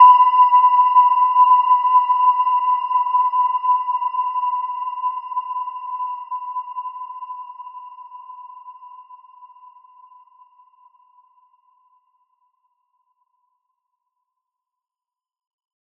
Gentle-Metallic-4-B5-f.wav